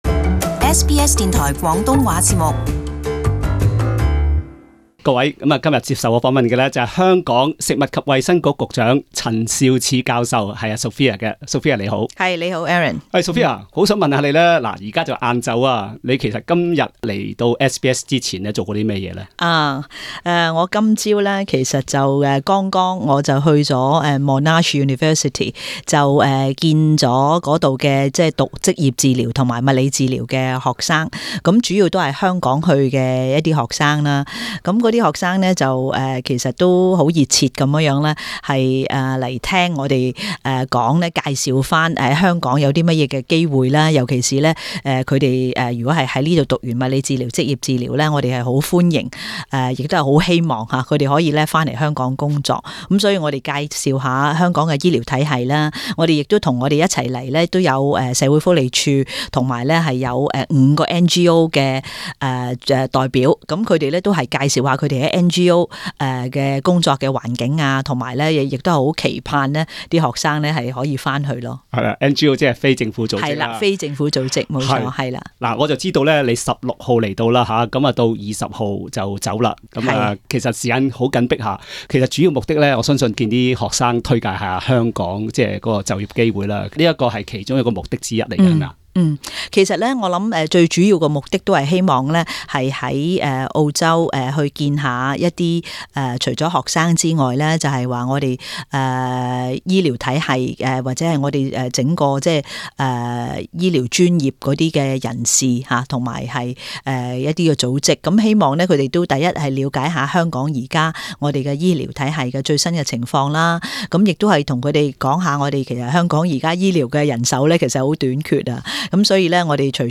【社區專訪】陳肇始親臨澳洲招攬本地受訓醫療人才